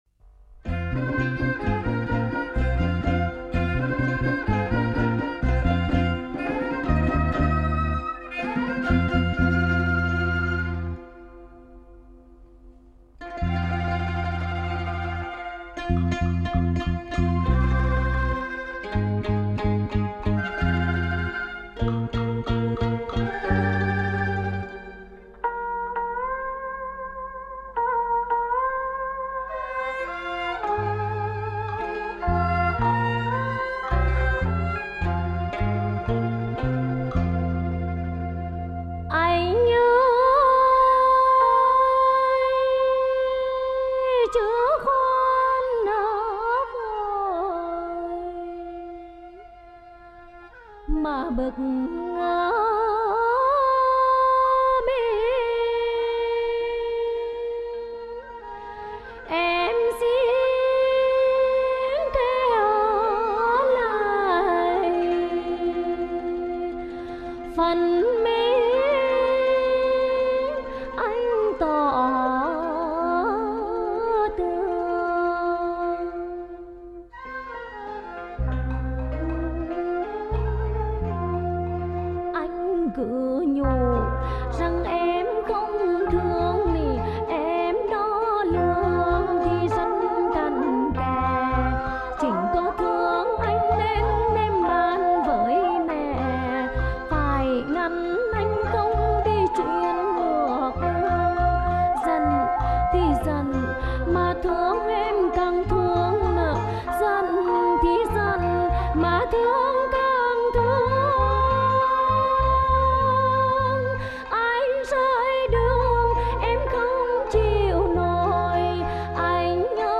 thuộc thể loại Dân ca Xứ Nghệ.